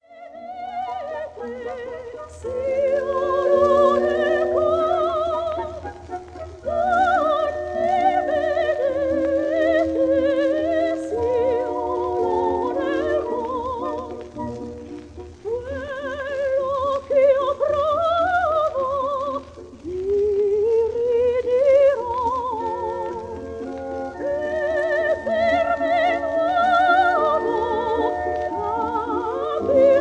soprano
Recorded in Teatra alla Scala on 30 July 1947